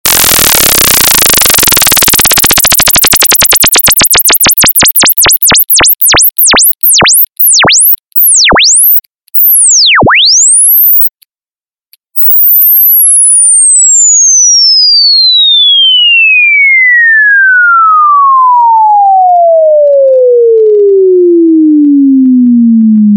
На этой странице собраны звуки, которые могут напугать или отпугнуть собак: ультразвуковые сигналы, резкие шумы, свистки и другие эффекты.
Звуки, пугающие собак: этим звуком можно напугать пса